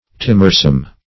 Search Result for " timorsome" : The Collaborative International Dictionary of English v.0.48: Timorsome \Tim"or*some\, a. Easily frightened; timorous.